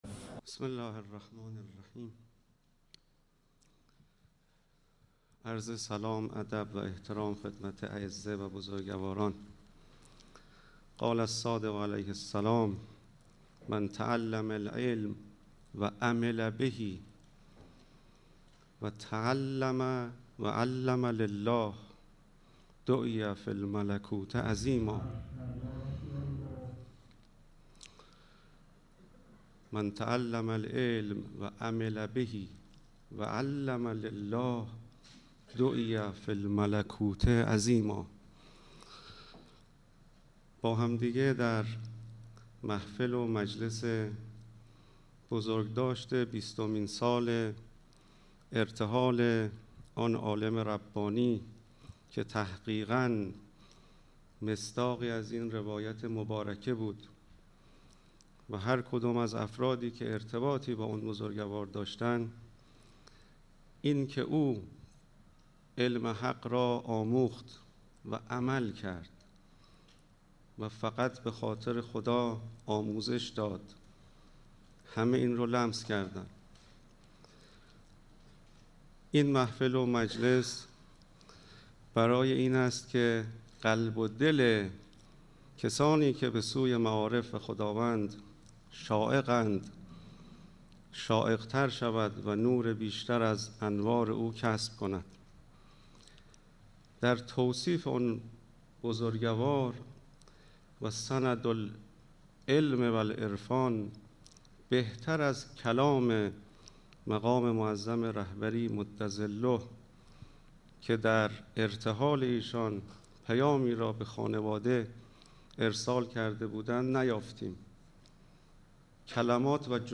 قرائت پیام تسلیت مقام معظم رهبری بمناسبت رحلت مرحوم علامه حسینی طهرانی و اعلام برنامه های همایش نور مجرد